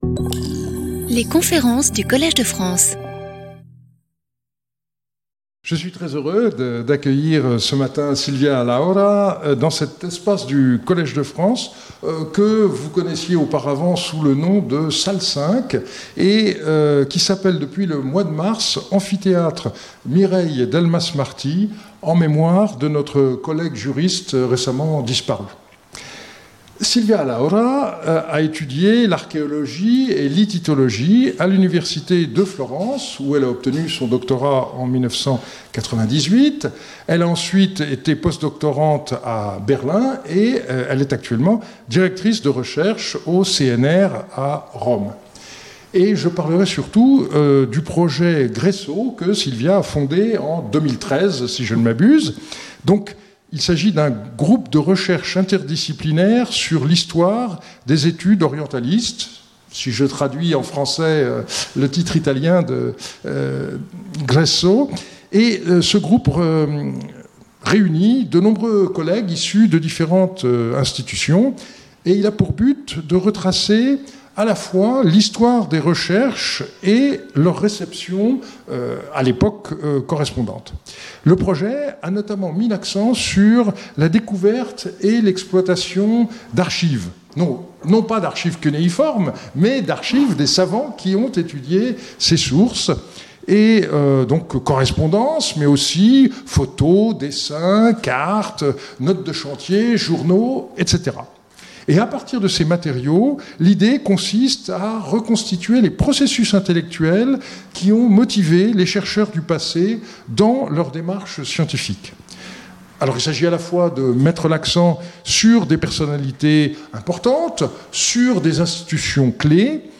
The lecture offers an overview of Anatolian Studies during the 1930s from both archaeological and philological perspectives.